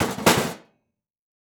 grilling